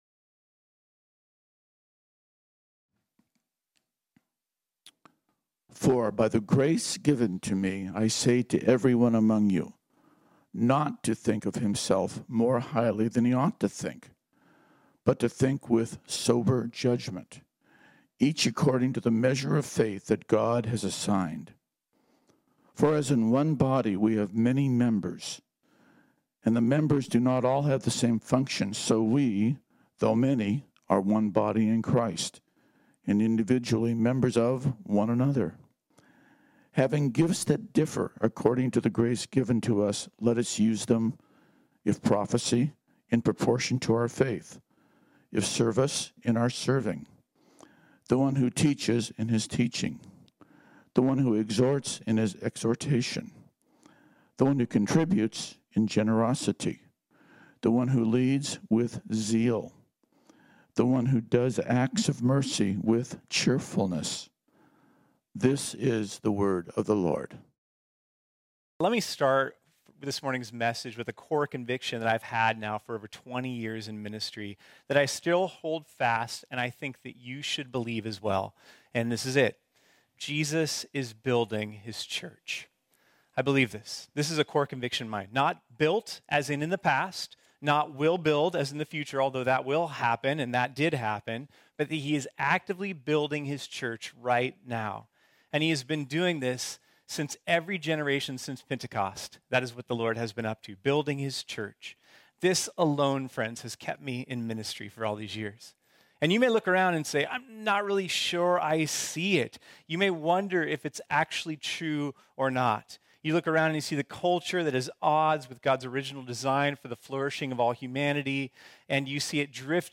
This sermon was originally preached on Sunday, July 4, 2021.